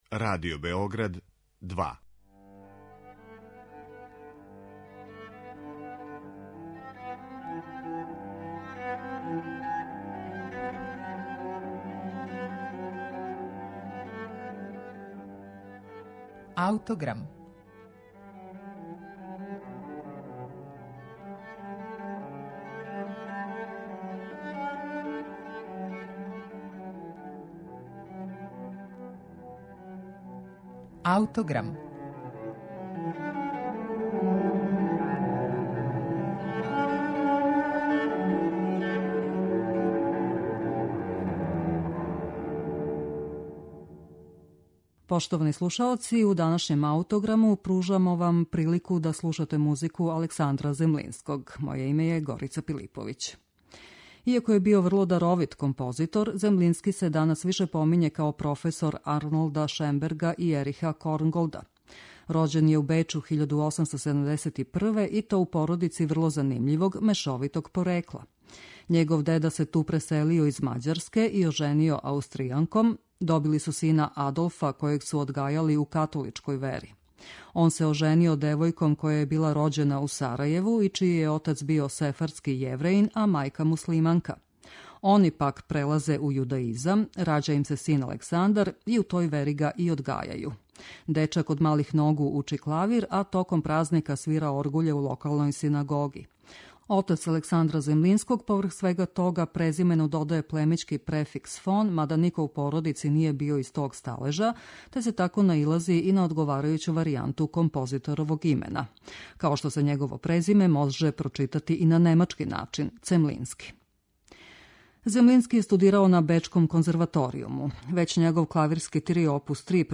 Рађено је према истоименој драми Оскара Вајлда као камерна опера великог психолошког интензитета. Сам Землински је адаптирао либрето, који се састоји од једне једине драмске ситуације - љубавног троугла, иначе његове омиљене теме. Ривалство двојице мушкараца у љубави према истој жени коначно ће довести до катастрофе, што Землински врло штедро потцртава еротизмом музике која асоцира на Штраусову Салому.